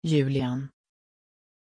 Pronunciation of Julián